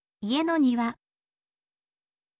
ie no niwa